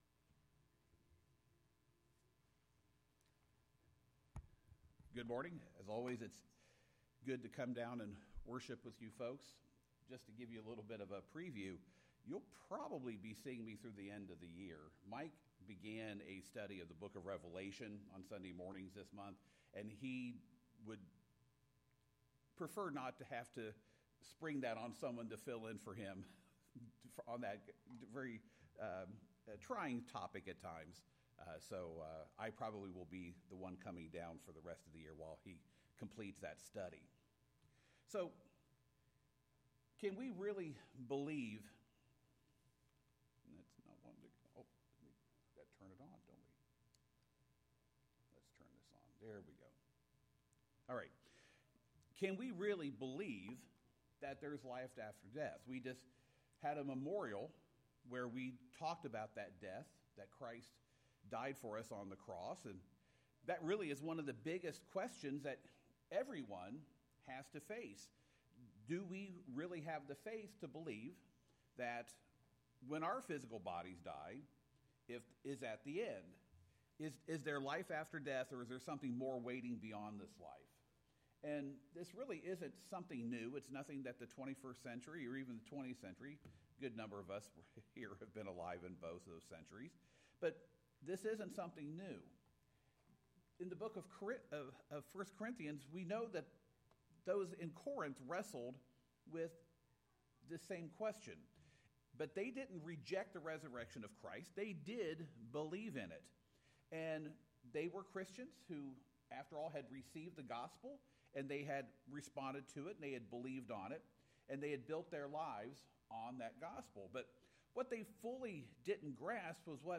This sermon addressed the question of life after death through 1 Corinthians 15:12–19, focusing on Paul’s consequences if there were no resurrection.